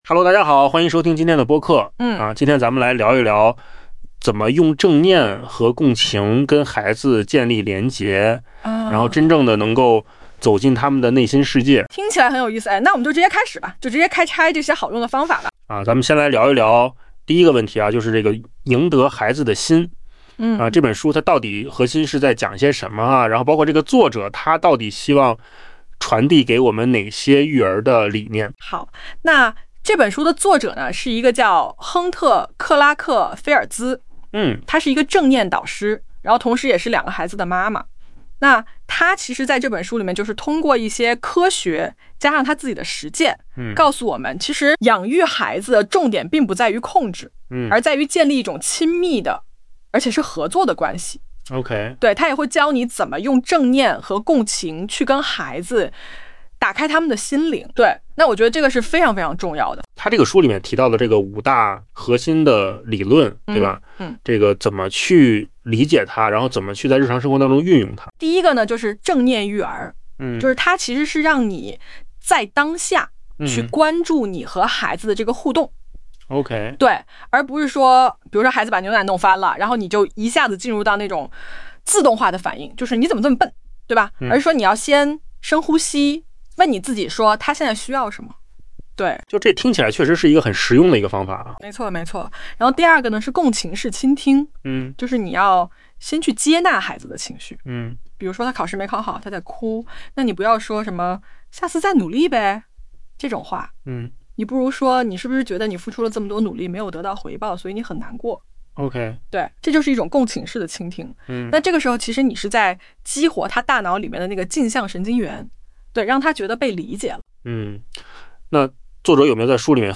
【书籍推荐】 《赢得孩子的心:与孩子建立亲密合作的关系》作者：亨特·克拉克-菲尔兹 全格式电子书+Ai播客
语音播客